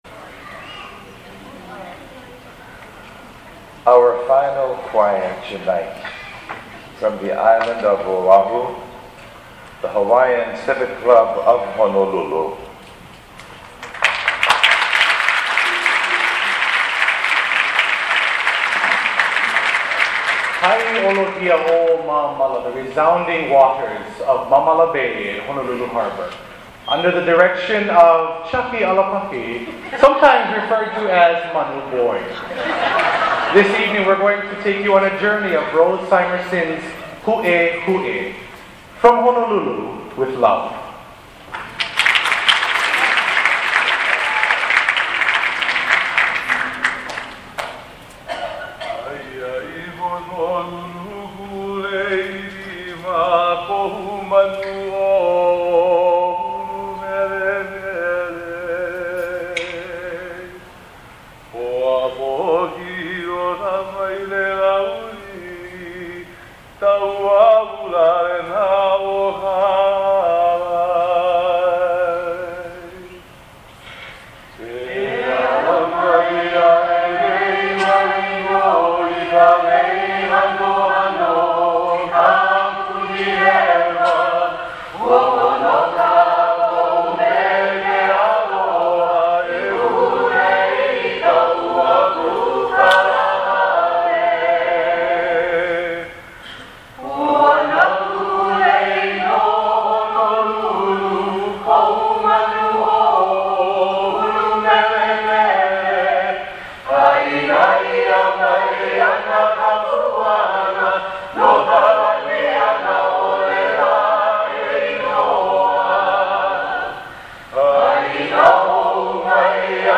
Waiting to go on, ‘Aha Mele competition.
Audio of the 2010 HCCH performance: